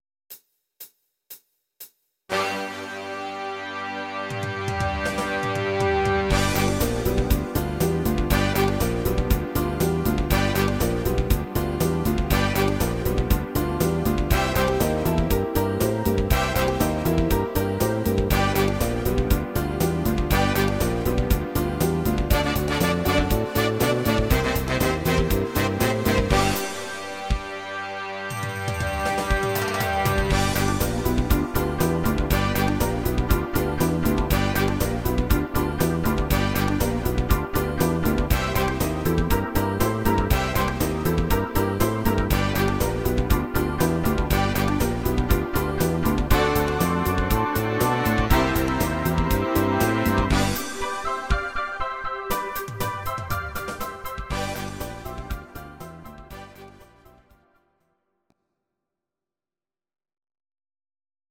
These are MP3 versions of our MIDI file catalogue.
Please note: no vocals and no karaoke included.
Freestyle & Madison version